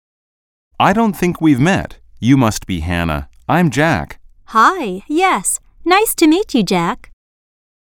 實境對話→